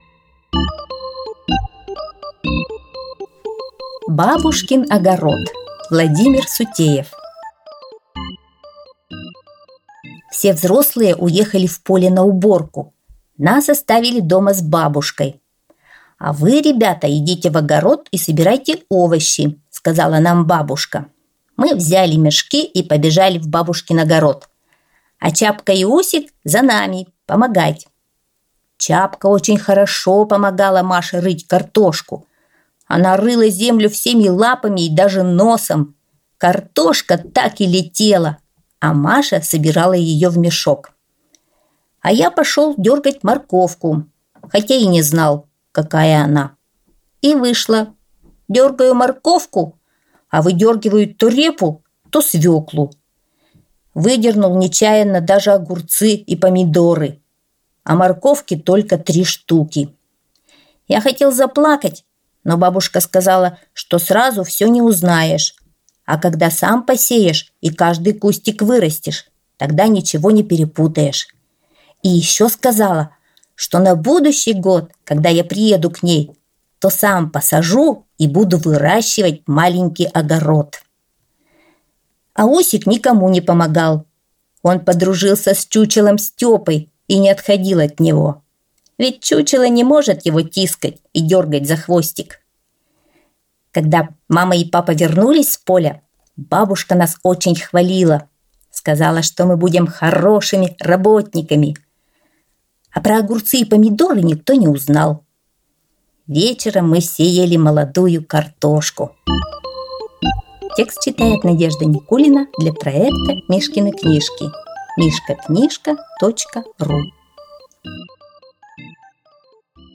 Аудиосказка «Бабушкин огород»